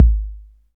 KIK 808 K 5.wav